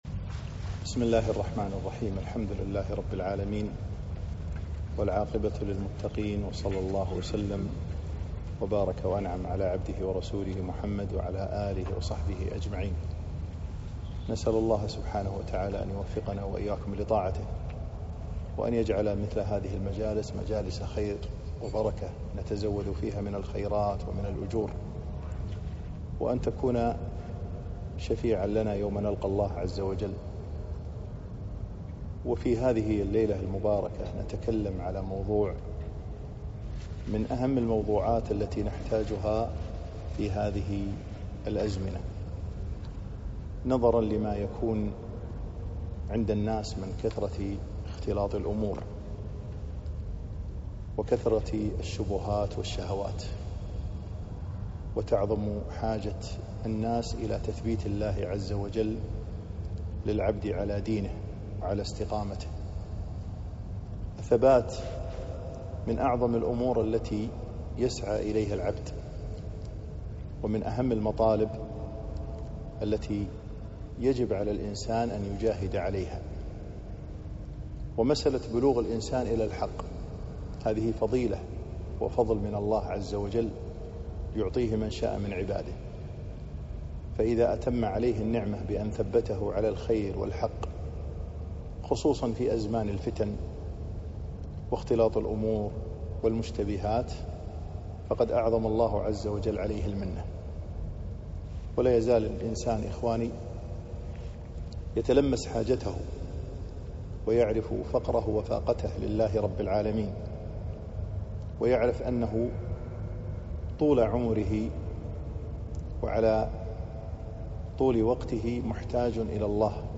محاضرة - الوسائل المعينة على الثبات